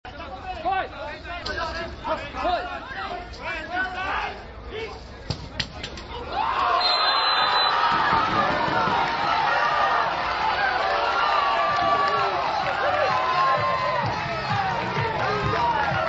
se desataba la euforia formato MP3 audio(0,12 MB) por un triunfo continental que ya se apuntaba al alcance de los dedos.